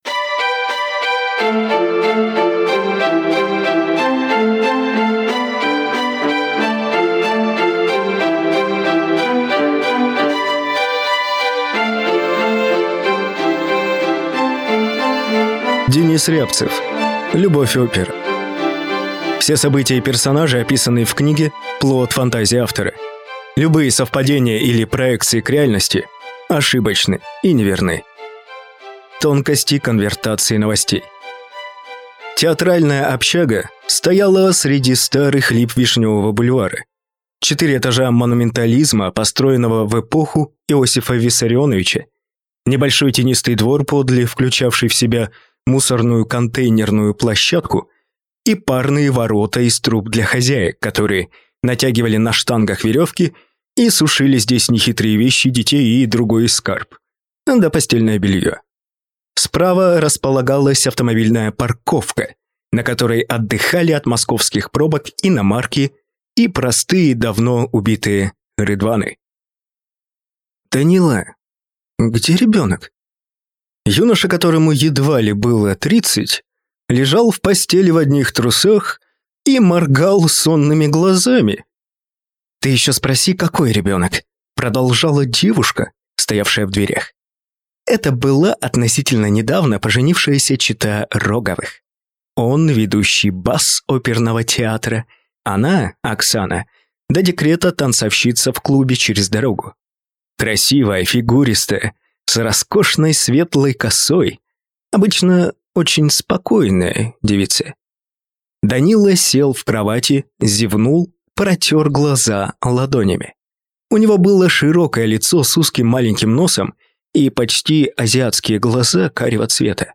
Аудиокнига Любовь опера | Библиотека аудиокниг